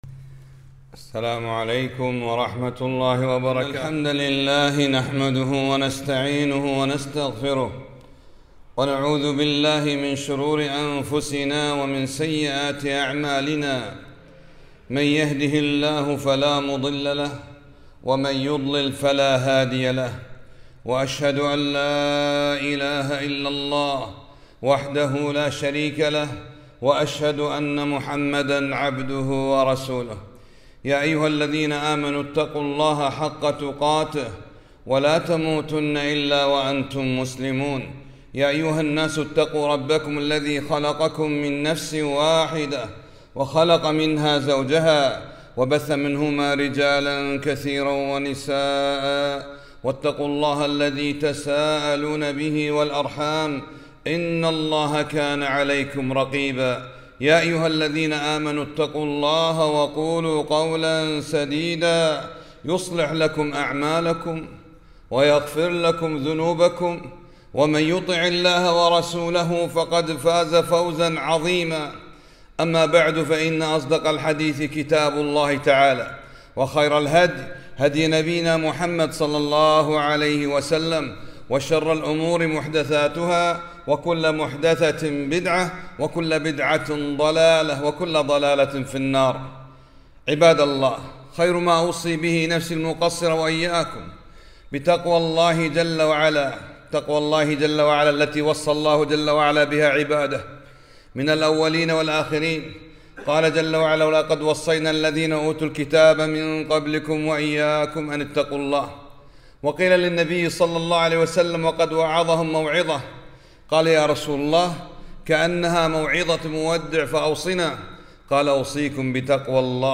خطبة - حديث